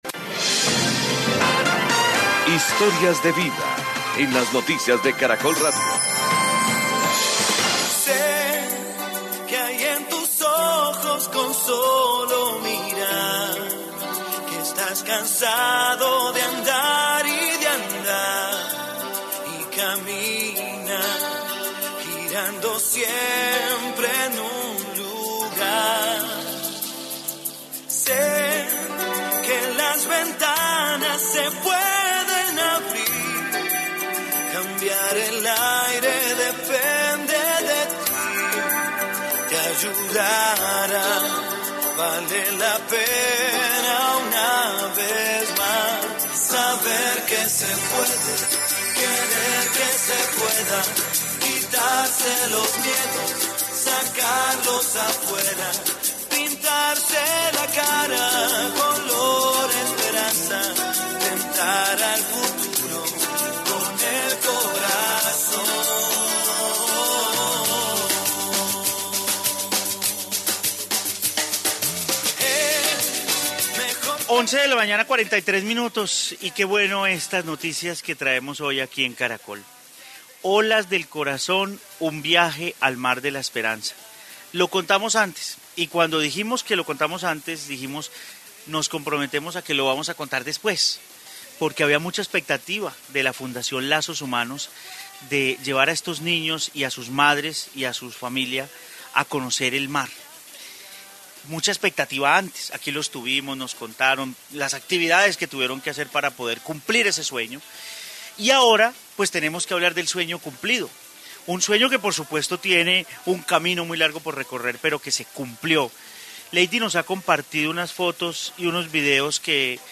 Informe sueño cumplido de conocer el mar